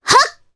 Mirianne-Vox_Attack1_jp.wav